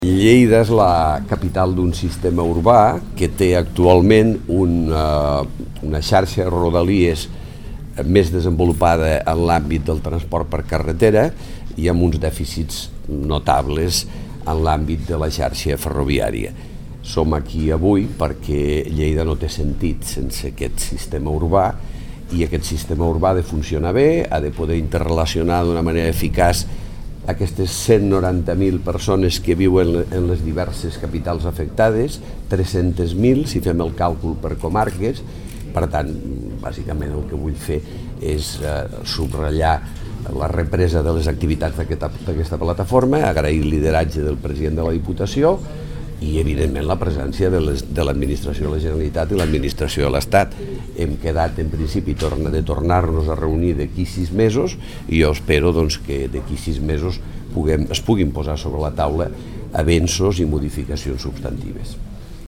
tall-de-veu-de-lalcalde-miquel-pueyo-sobre-la-reunio-de-la-plataforma-per-la-millora-de-la-linia-de-tren-lleida-manresa